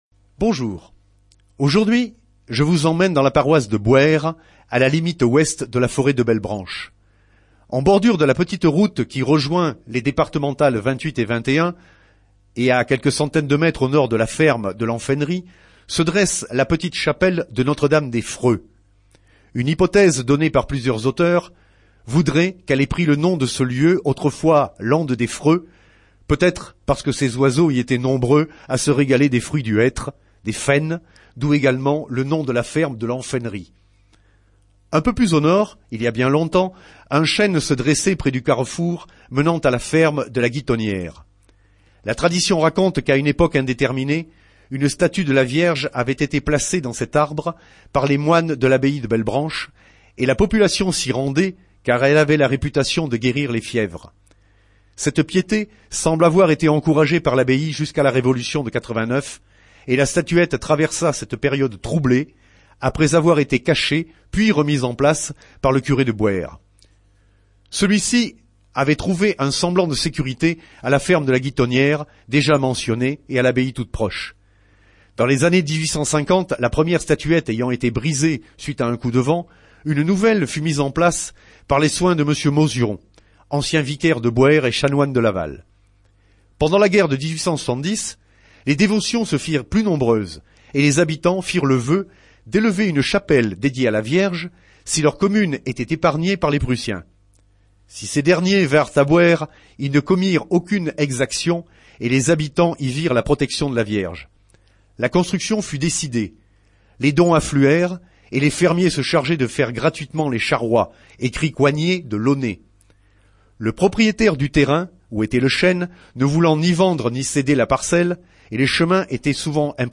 La S.AH.M. sur Radio Fidélité